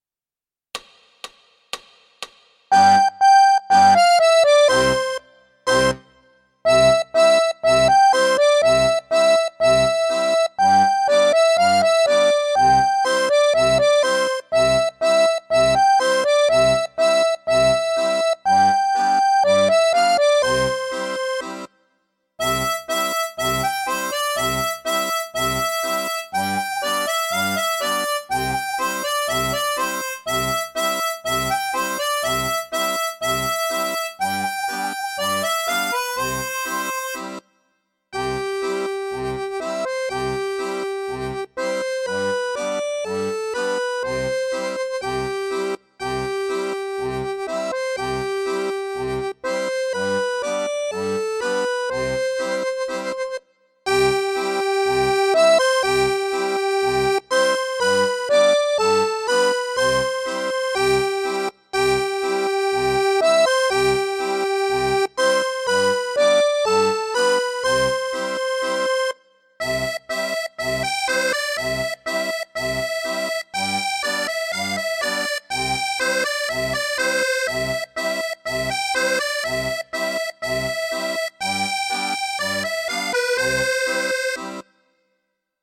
Fisarmonica, Capitolo V, allargamenti stringimenti
14 – Marciando - (unite) Ignoto –(in Do e Sol magg.)